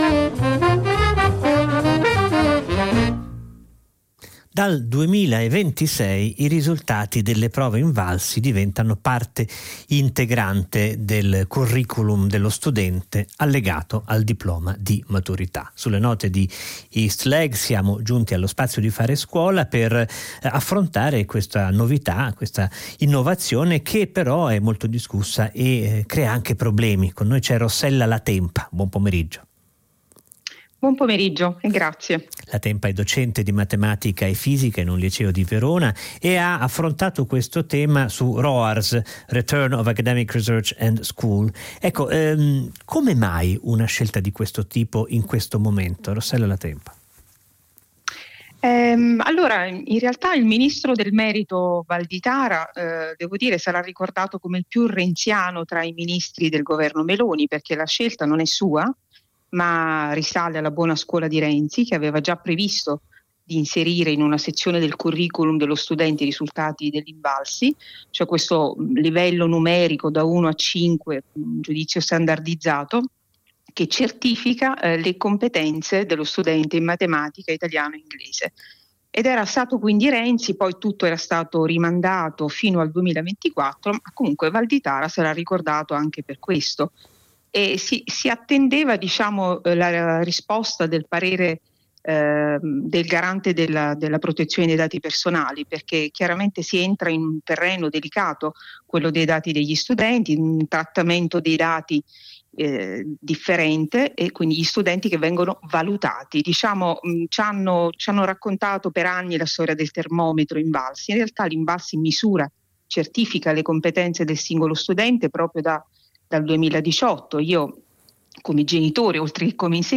Test INVALSI nel curriculum dello studente: un’intervista
Quest’anno però i risultati di quei test entreranno nel loro curriculum digitale. Abbiamo avuto modo di esprimere la nostra valutazione su questa scelta in una breve intervista a Radio 3 Fahrenheit del 17 Febbraio scorso, che riportiamo di seguito.